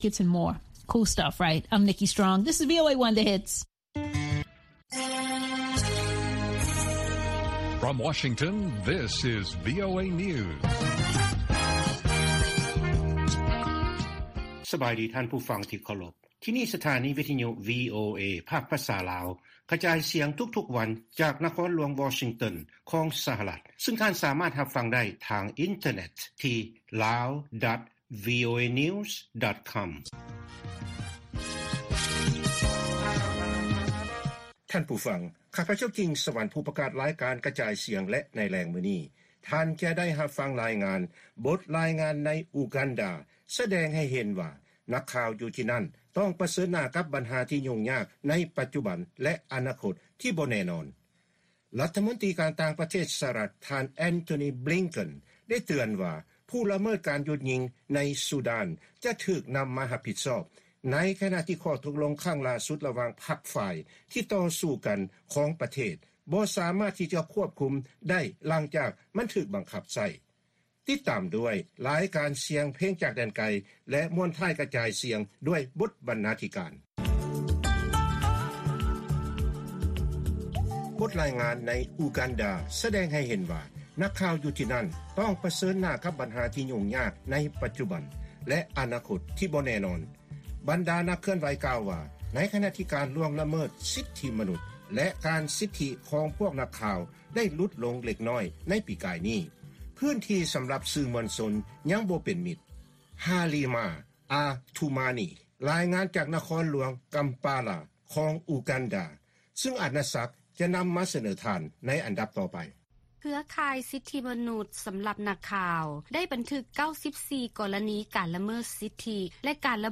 ລາຍການກະຈາຍສຽງຂອງວີໂອເອ ລາວ: ນັກເຄື່ອນໄຫວອູການດາ ກ່າວວ່າ ອະນາຄົດຂອງສື່ມວນຊົນບໍ່ແນ່ນອນ